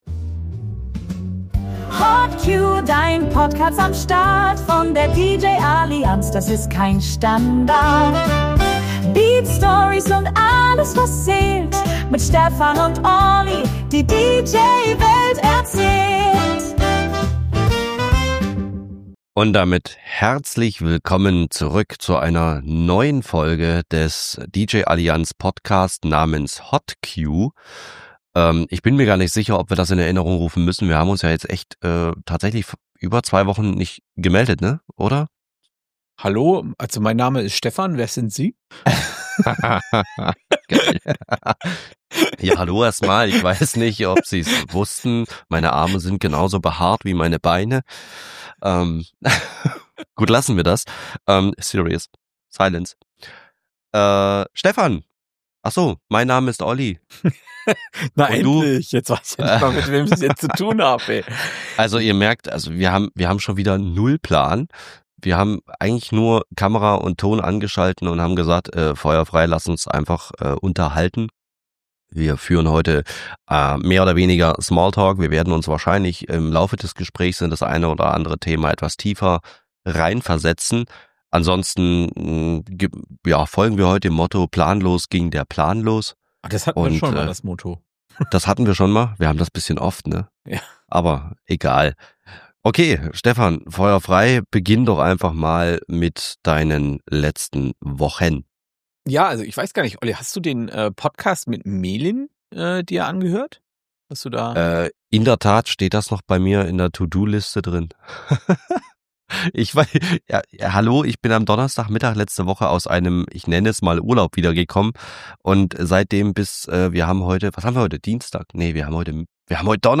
Außerdem wird es tiefgründig: Welche Kunden buchen welchen DJ – und warum? Ein Gespräch über Wirkung, Zielgruppen und das unterschätzte Marketing zwischen Pädagogen und Unternehmern. Zwischendurch geht’s um Urlaubsfails, ChatGPT auf der Autobahn und das neue DJ-Vortragskonzept der beiden.